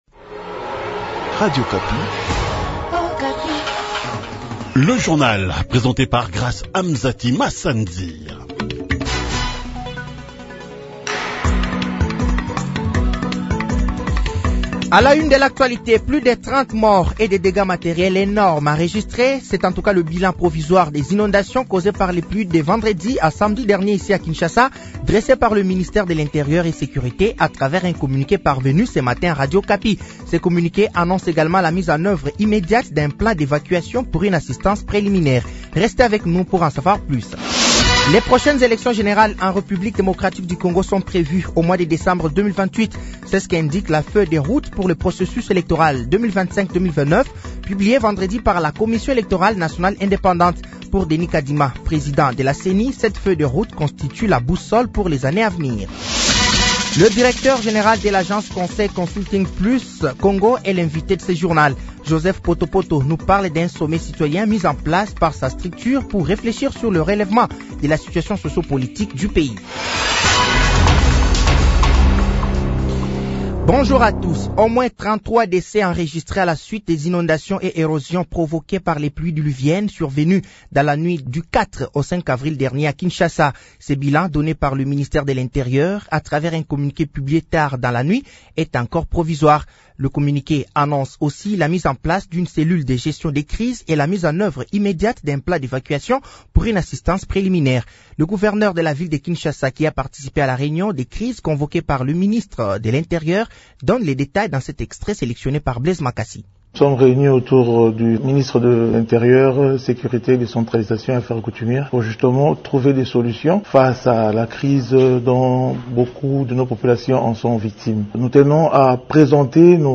Journal français de 7h de ce lundi 07 avril 2025